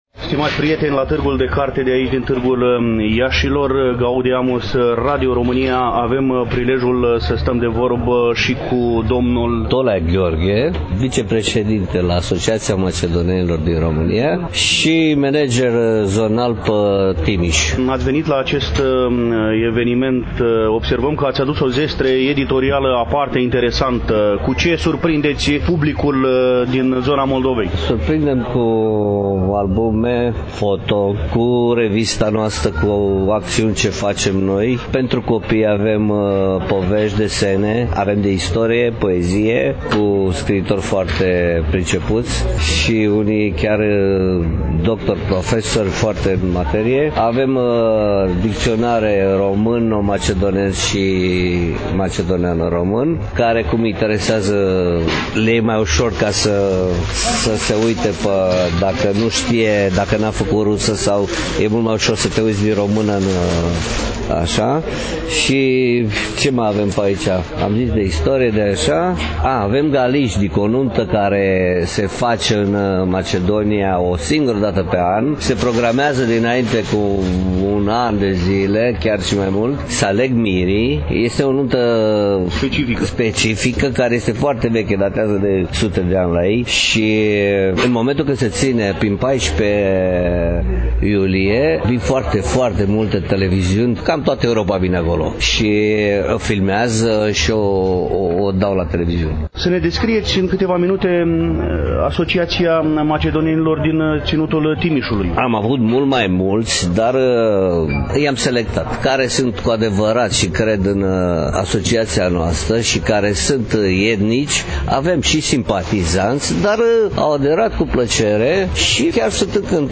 Relatăm, astăzi, de la standul Asociației Macedonenilor din România